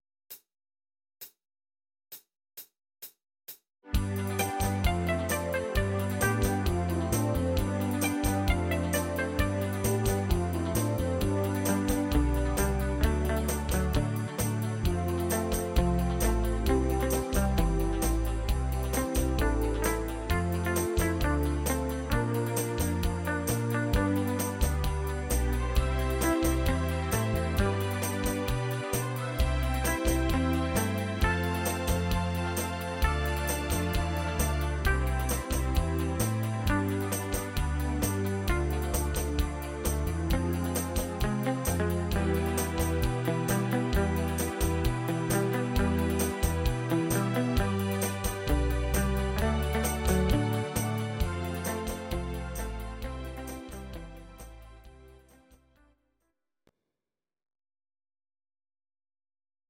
These are MP3 versions of our MIDI file catalogue.
Your-Mix: Instrumental (2065)